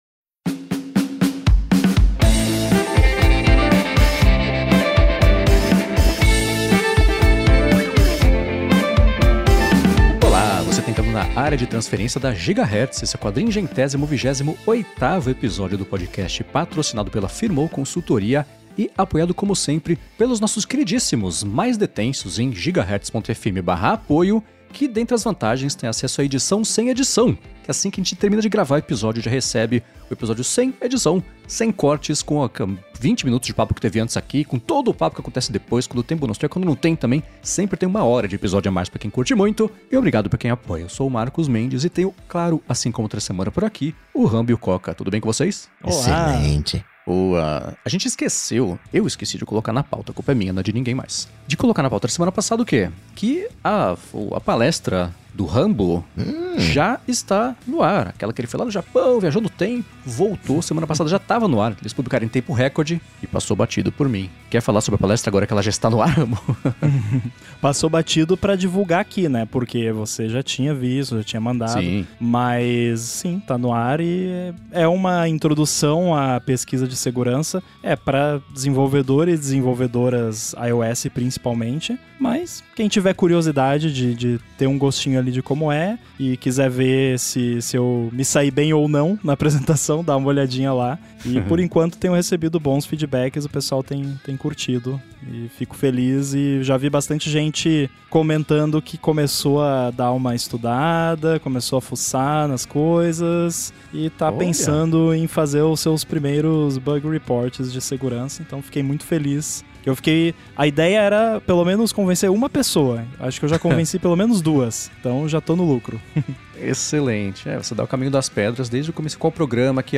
Um podcast semanal sobre tecnologia, feito por 4 podcasters que já respiram o assunto diariamente.